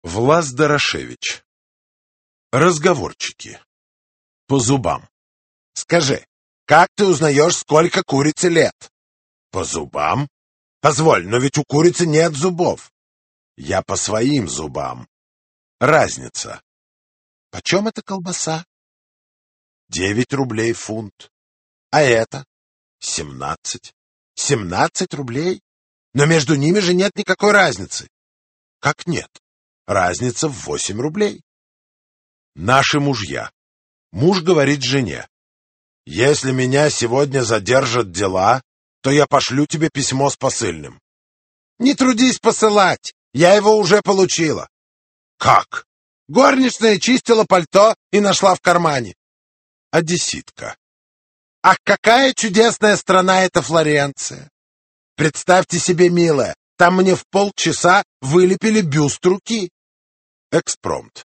Аудиокнига Классика русского юмористического рассказа № 1 | Библиотека аудиокниг